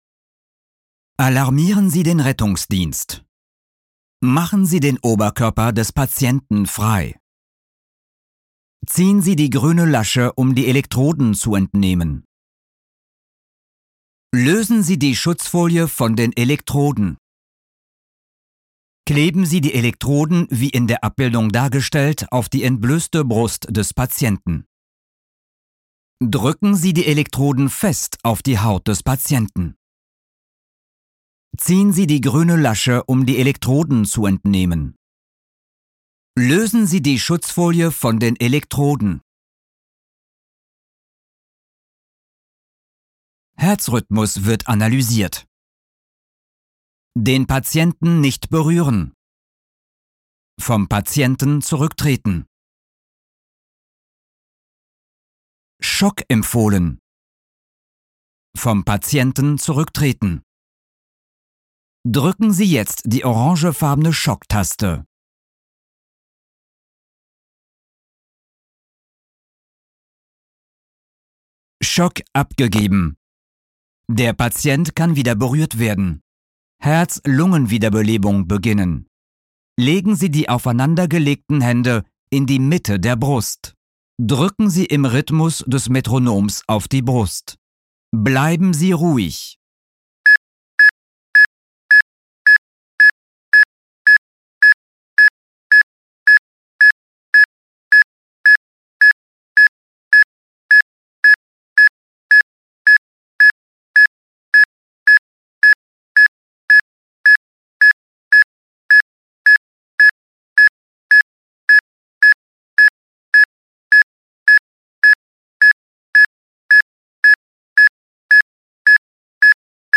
Audio: Anweisungen Defibrillator (SAM 500p)